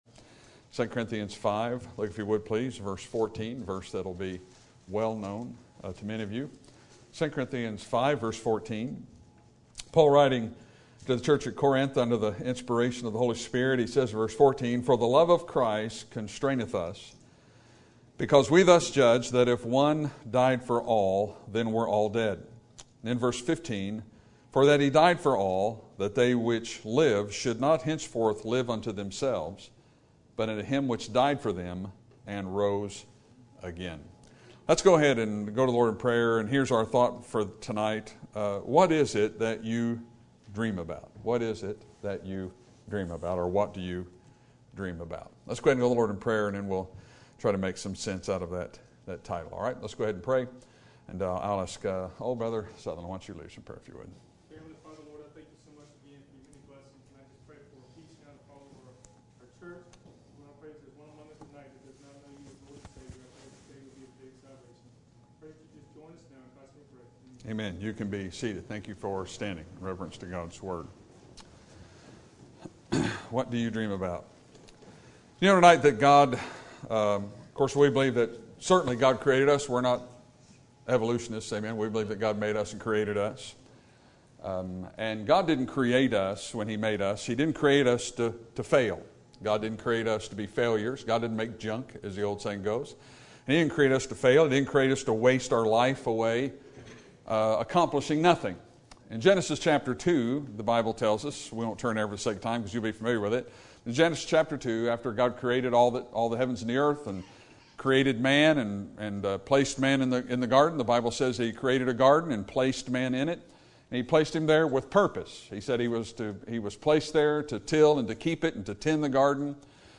Sermon Topic: General Sermon Type: Service Sermon Audio: Sermon download: Download (25.4 MB) Sermon Tags: 2 Corinthians Paul Dream Destination